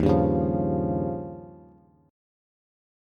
Eb9 Chord
Listen to Eb9 strummed